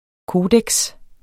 Udtale [ ˈkoːdεgs ]